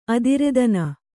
♪ adiredana